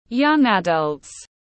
Trẻ vị thành niên tiếng anh gọi là young adult, phiên âm tiếng anh đọc là /ˌjʌŋ ˈæd.ʌlt/.